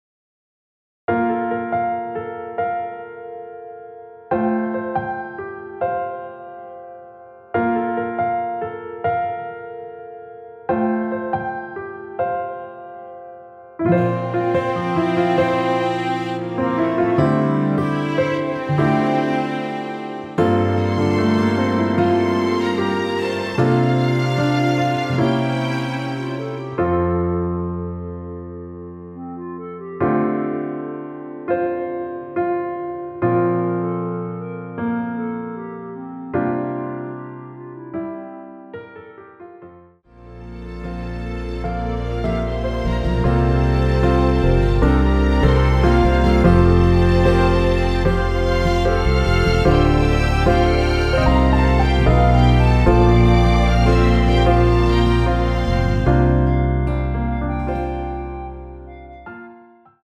이곡은 프리템포라 앞부분 멜로디 소리가 늘어지게 들릴수도 있으나
원곡과 똑같은 템포로 제작하였으니 미리듣기 확인후 참고하여 이용하여주세요.
원키에서(-8)내린 멜로디 포함된 MR입니다.
앞부분30초, 뒷부분30초씩 편집해서 올려 드리고 있습니다.